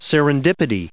/sɛrən’dɪpɪti/